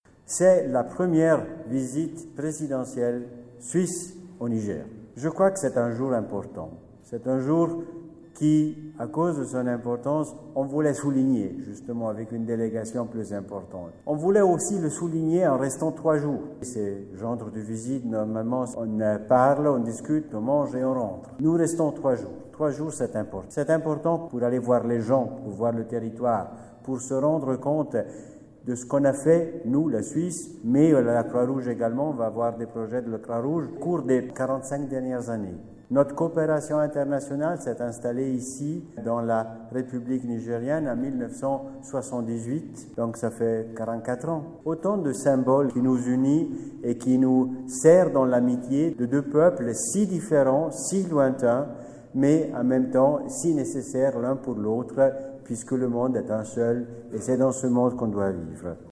Extrait conférence de presse du Président de la Confédération Suisse